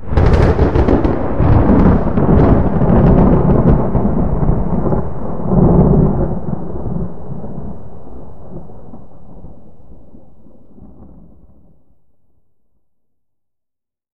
thundernew4.ogg